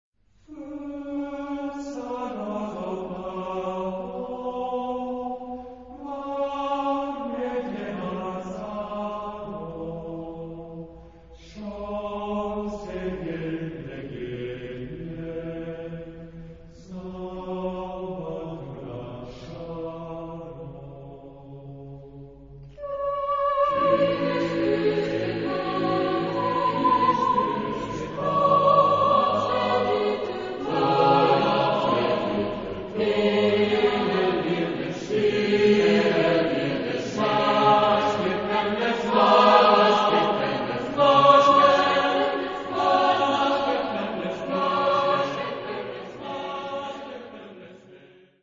Genre-Style-Form: Secular ; Popular ; Choir
Type of Choir: SATB  (4 mixed voices )
Tonality: D dorian